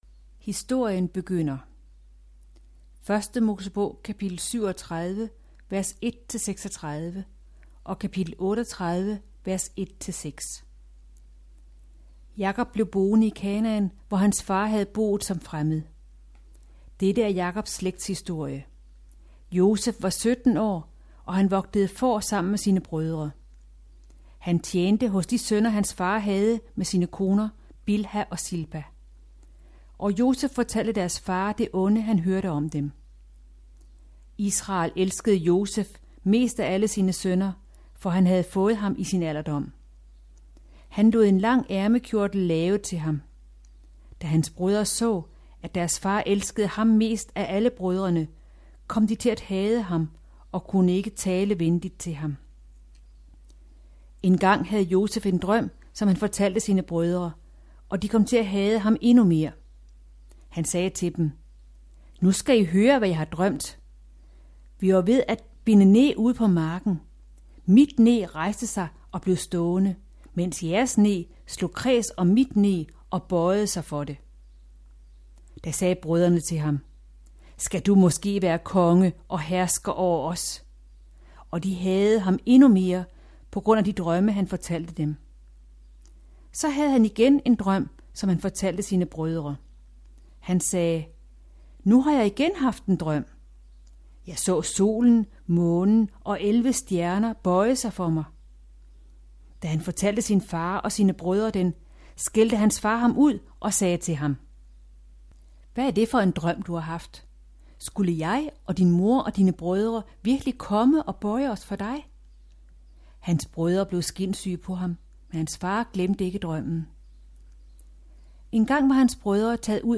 Hør et uddrag af Nådeslægten Nådeslægten Format MP3 Forfatter Francine Rivers Bog Lydbog E-bog 249,95 kr.